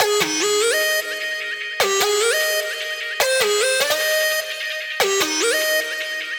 150BPM Lead 07 G#Maj.wav